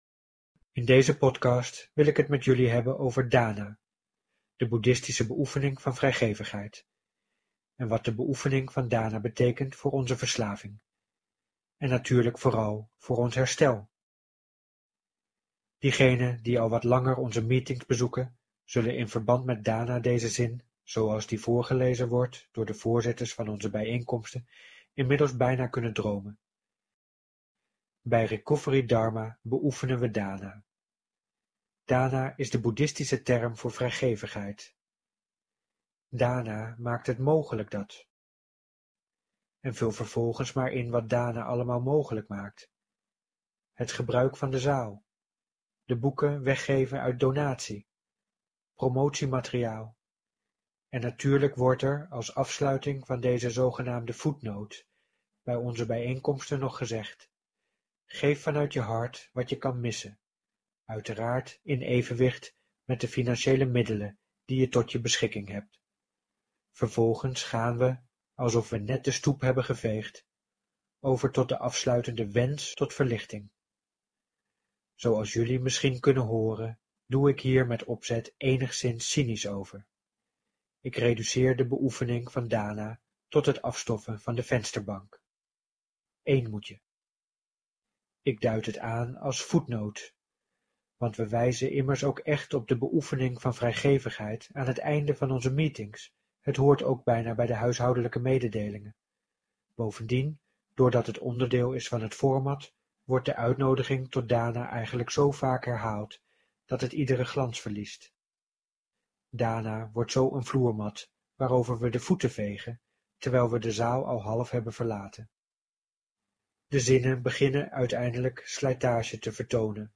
Gesproken-blog-Dana-in-Herstel.mp3